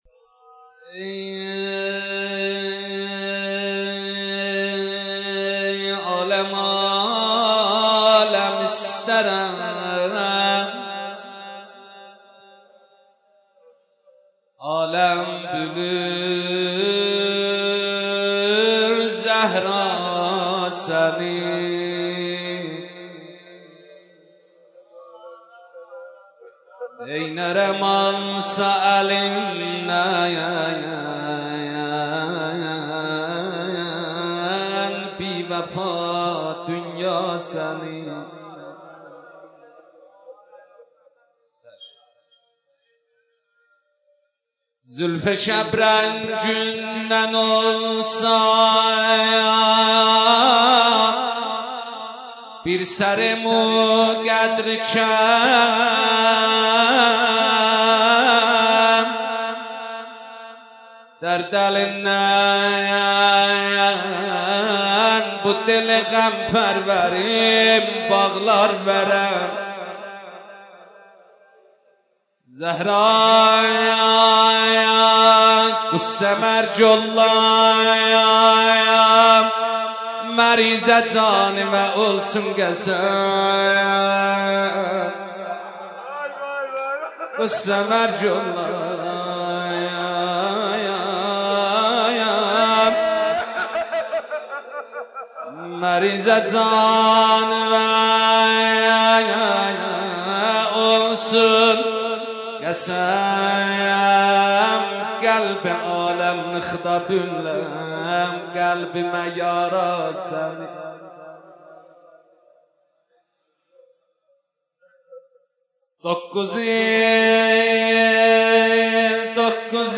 دانلود مداحی درد من - دانلود ریمیکس و آهنگ جدید
ذکر مصیبت شهادت شهیده راه ولایت حضرت زهرا(س